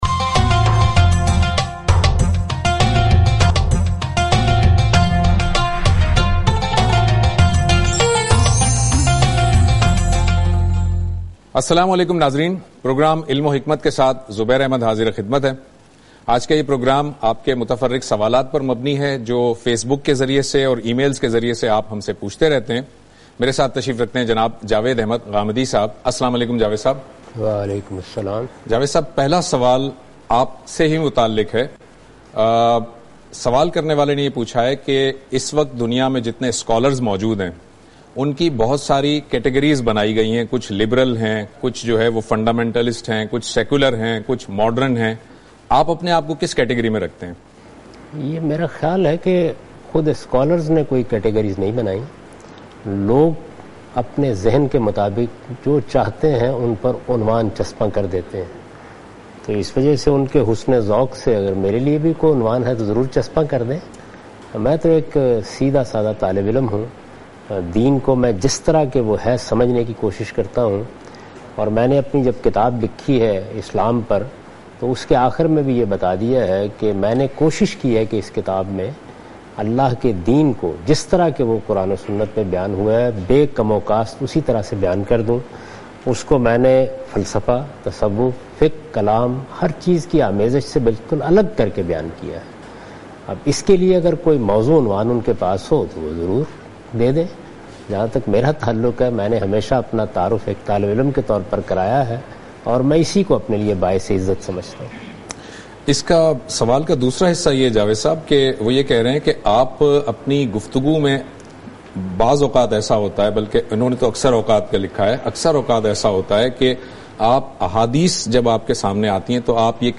In this program Javed Ahmad Ghamidi answers miscellaneous questions.